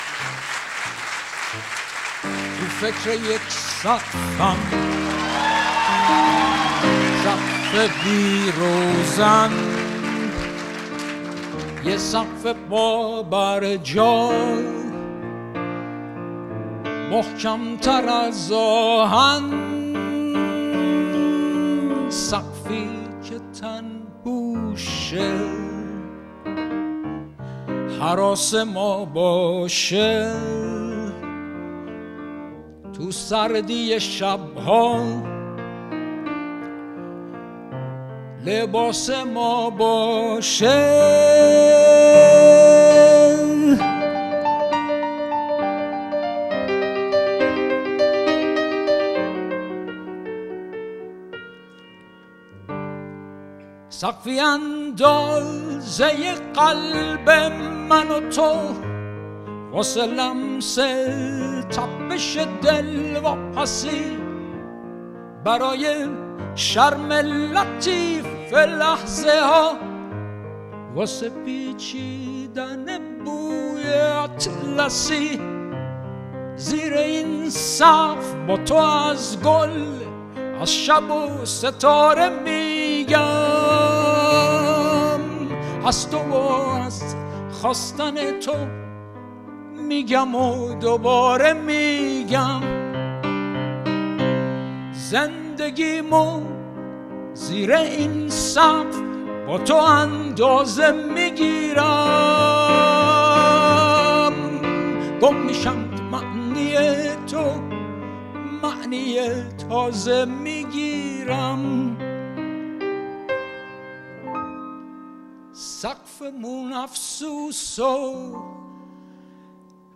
اجرای کنسرت آمریکا